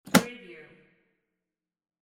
Briefcase lock sound effect .wav #4
Description: The sound of a briefcase lock snapping open or close
Properties: 48.000 kHz 16-bit Stereo
Keywords: briefcase, open, opening, close, closing, latch, unlatch, business, case, lock, locking, unlock, unlocking, snap, click
briefcase-lock-preview-4.mp3